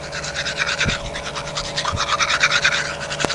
Brushing Teeth Sound Effect
Download a high-quality brushing teeth sound effect.
brushing-teeth.mp3